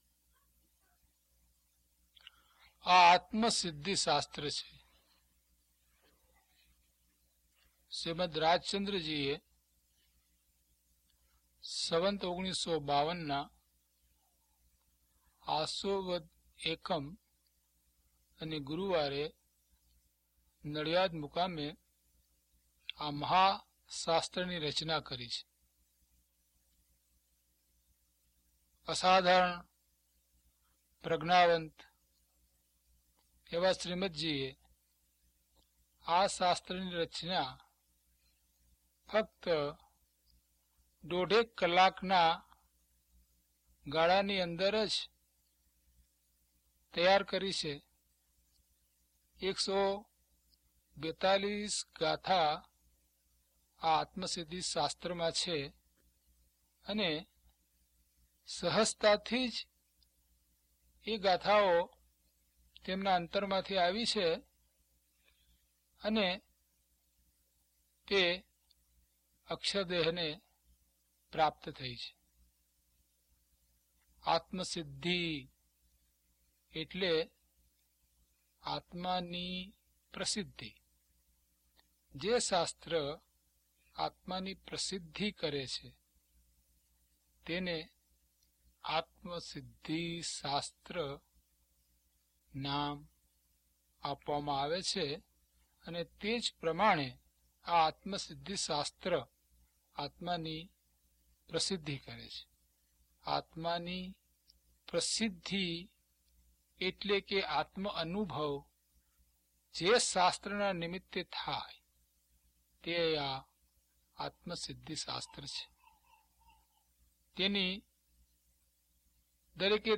DHP010 Atmasiddhi Vivechan 1 - Pravachan.mp3